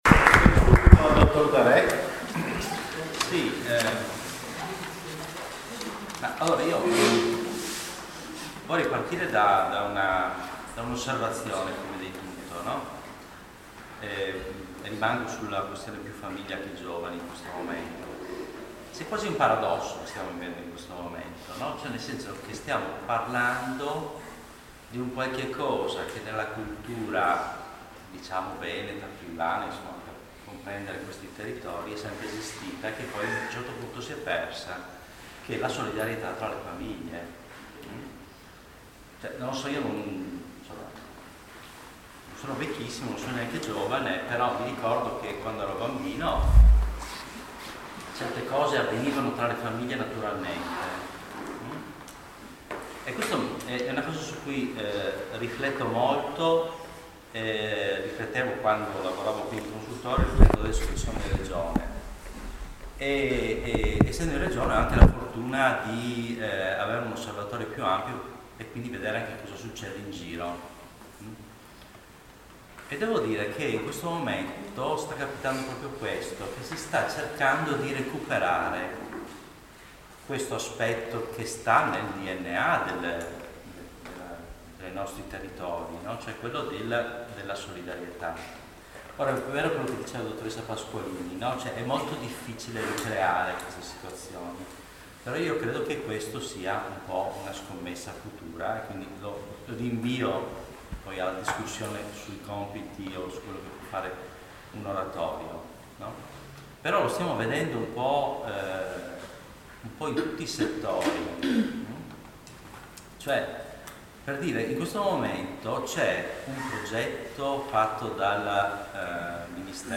Portogruaro 10 Aprile 2014 - Convegno: Oratorio 1+1=3 Sinergie impossibili presso l'Oratorio Calasanzio tra gli operatori di formazione dei giovani per ascoltare cosa ci si aspetta dall'Oratorio.